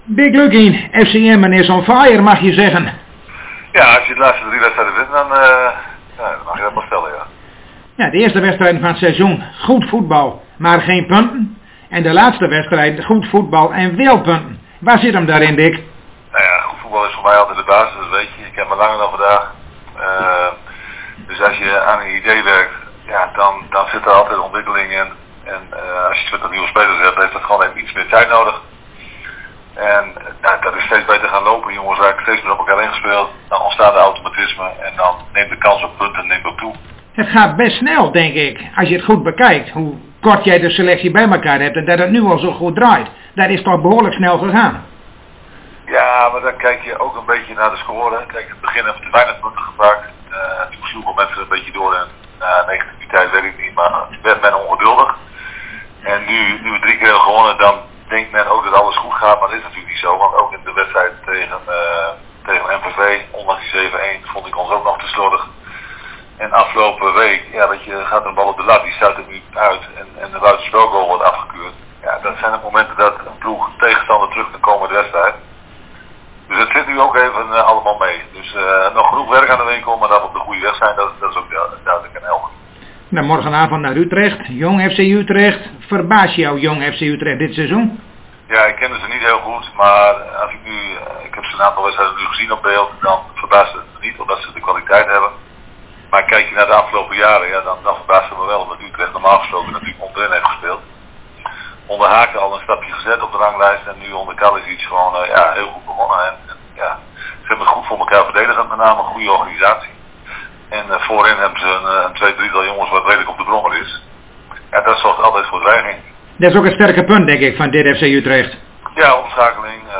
In gesprek met Dick Lukkien over Jong FC Utrecht - FC Emmen